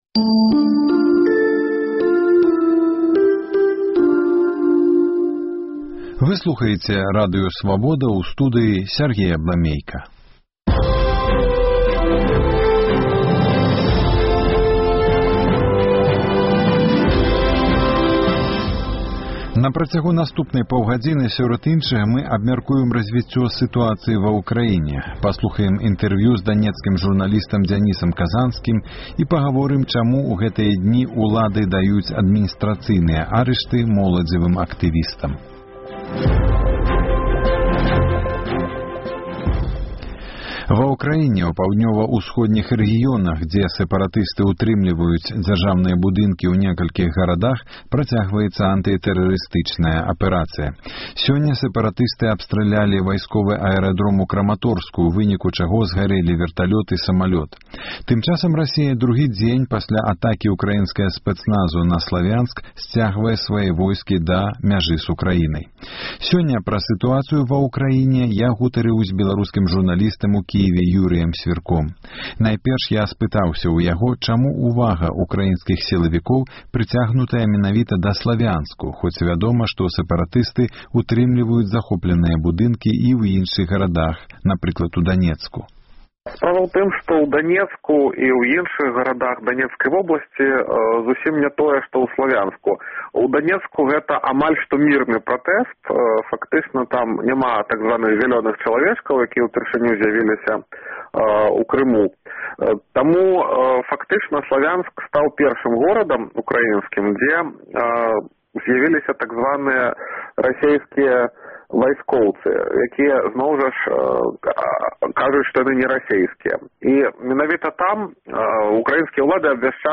Данецкі журналіст у інтэрвію нашаму Радыё заявіў, што падзеі на ўсходзе Ўкраіны – гэта бунт адчаю, бо ў людзей няма працы і пэрспэктываў.